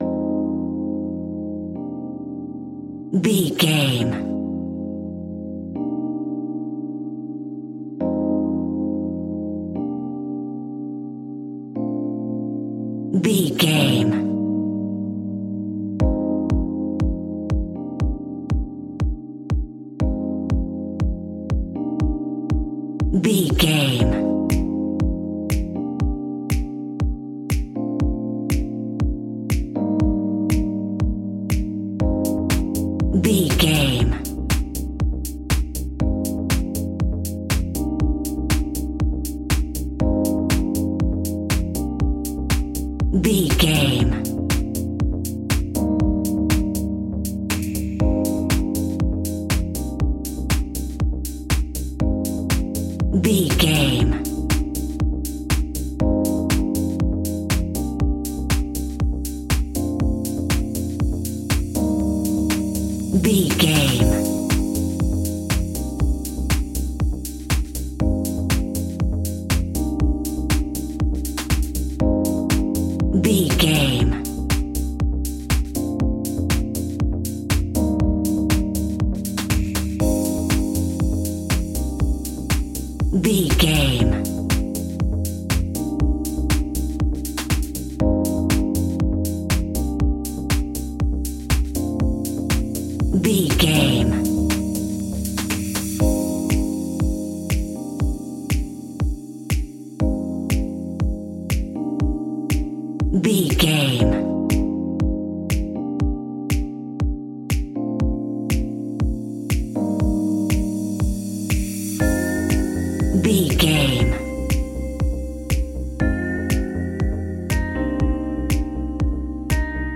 Aeolian/Minor
uplifting
driving
energetic
funky
synthesiser
drum machine
electro house
funky house
synth leads
synth bass